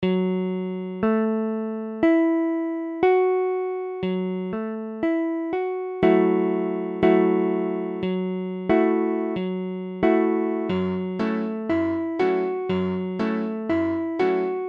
Illustration sonore : II_Fdiesem7.mp3
F#m7m7 : accord de Fa di�se mineur septi�me Mesure : 4/4
Tempo : 1/4=60